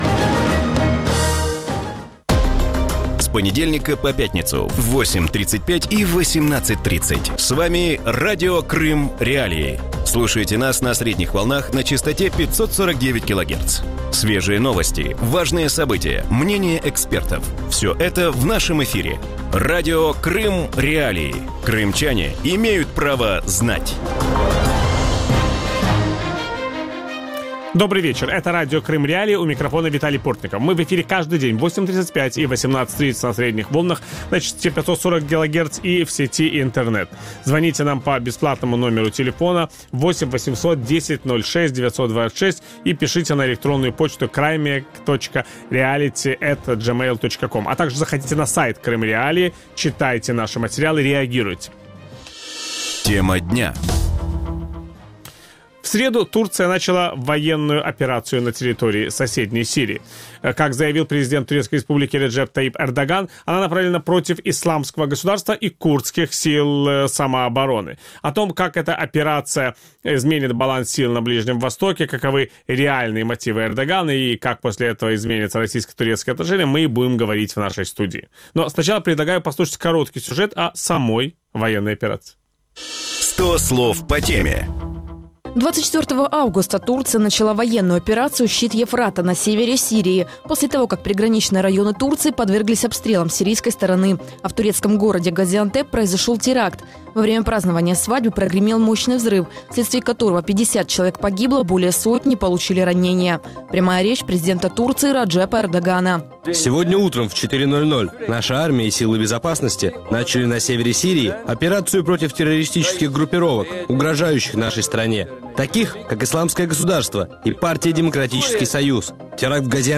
У вечірньому ефірі Радіо Крим.Реалії обговорюють початок військової операції Туреччини в Сирії. Як турецький фактор позначиться на ситуації у регіоні, як будуть складатися російсько-турецькі відносини і чи можна очікувати перемоги над ІГІЛ?
Ведучий: Віталій Портников.